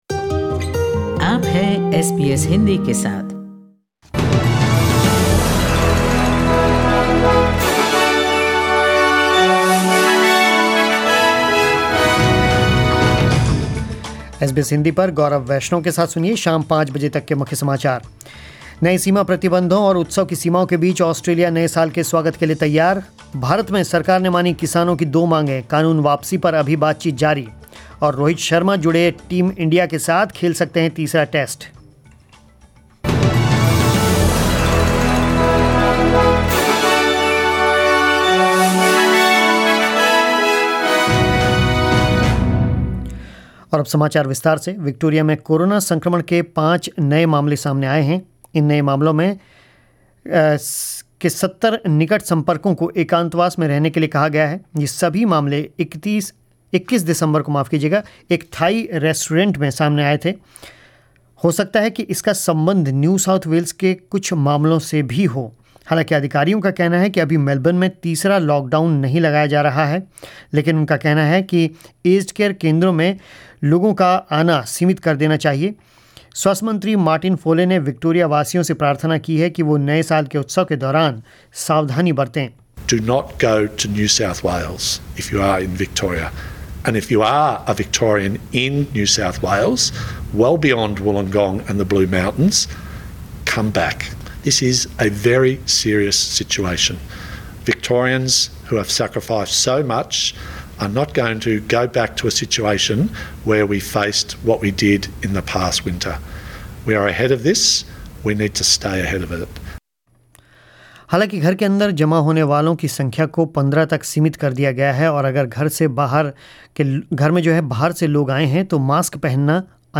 News in Hindi 31 December 2020 In India, farmer Unions-Centre talks: ‘Consensus reached on 2 issues’, says agriculture minister Narendra Tomar. //** Australia marks the new year with several COVID-19 outbreaks, new border restrictions and limited celebrations.